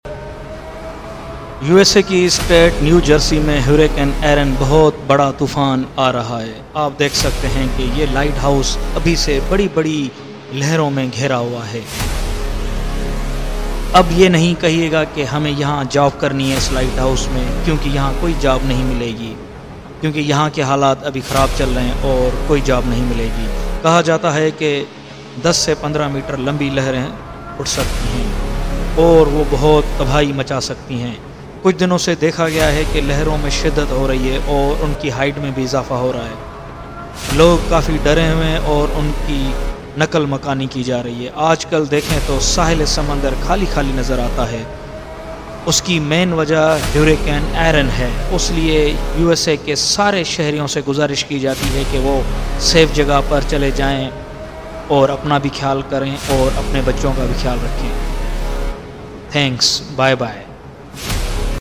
Hurricane Erin Weather Forecast In sound effects free download